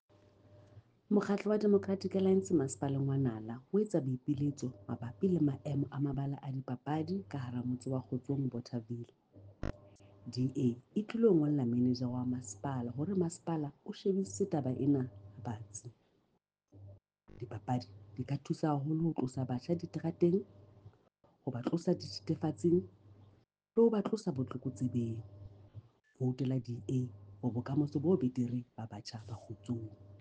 Sesotho soundbites by Cllr Mahalia Kose.